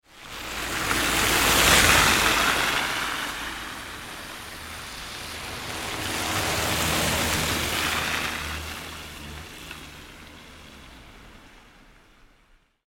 Cars-driving-on-wet-road-sound-effect.mp3